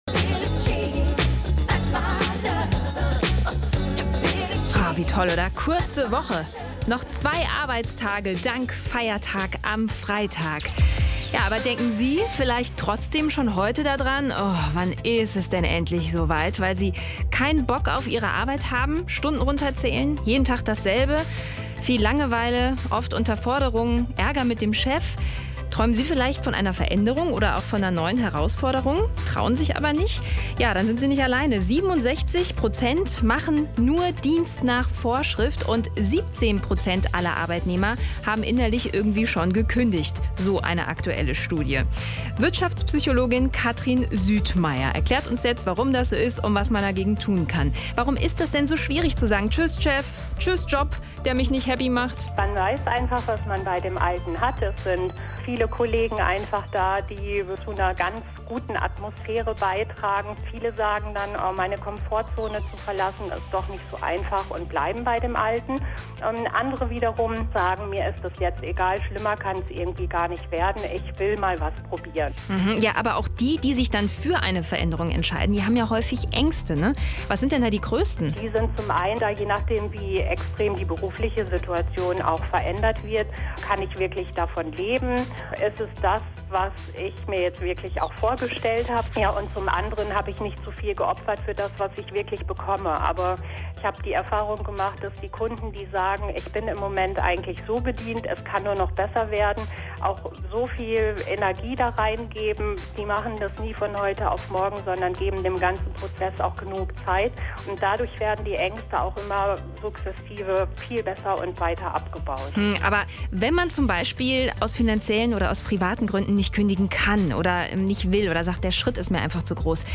Ein Interview mit hr3